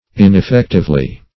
Ineffectively \In`ef*fect"ive*ly\, adv.